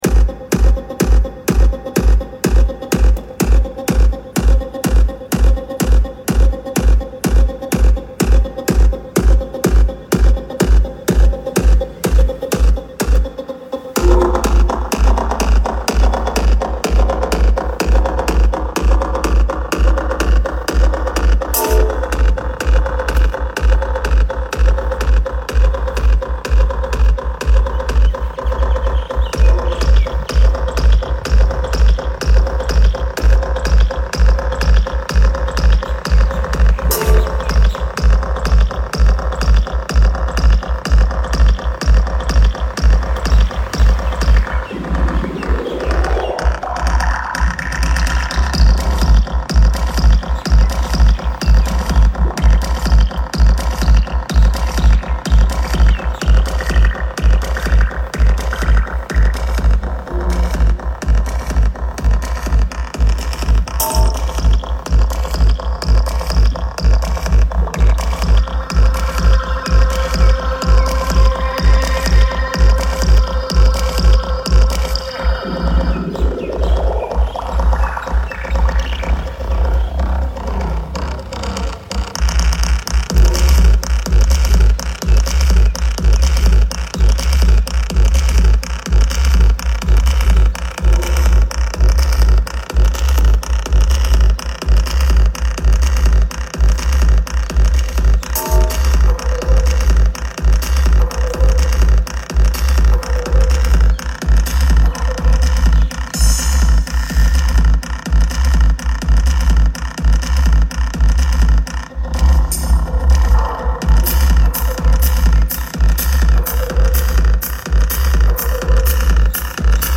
BMW F44 NITROSOUND 🇲🇺 sound effects free download